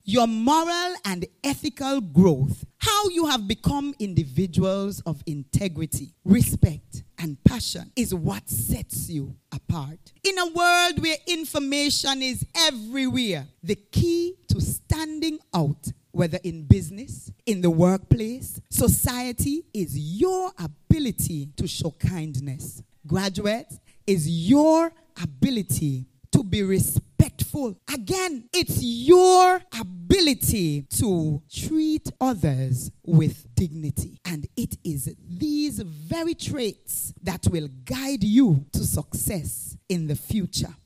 The event took place at the Nevis Cultural Village in Charlestown.